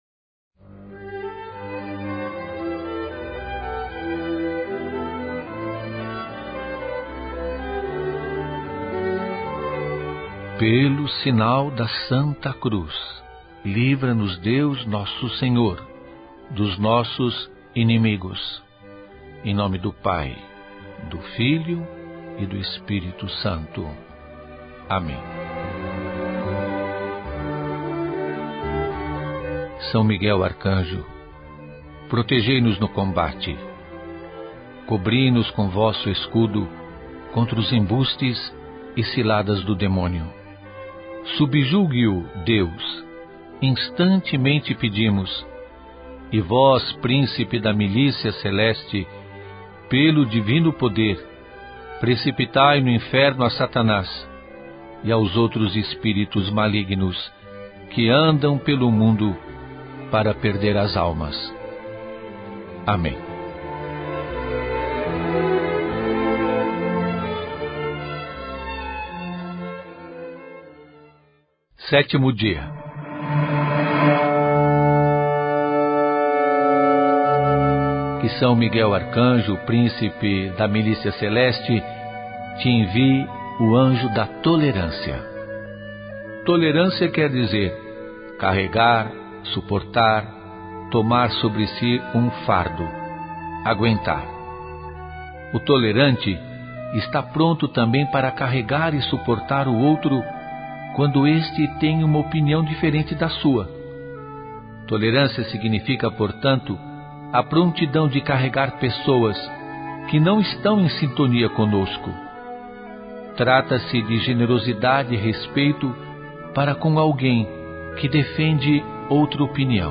Julio Lancellotti. 7º dia: Esta novena foi produzida nos estúdios da Universidade São Judas Tadeu
Neste mês em que se celebra a festa do padroeiro, participe da Novena em honra a São Miguel Arcanjo, baseada no livro “50 Anjos para a Alma” do monge Anselm Grun, na voz do Pe. Julio Lancellotti.